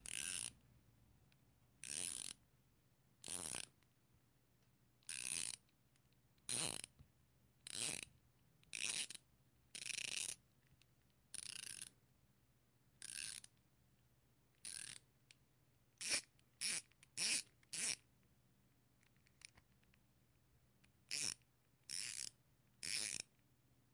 荧光笔
描述：拧紧并拧下荧光笔标记的顶部。
Tag: 荧光笔 标记